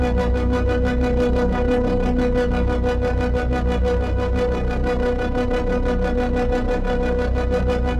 Index of /musicradar/dystopian-drone-samples/Tempo Loops/90bpm
DD_TempoDroneA_90-B.wav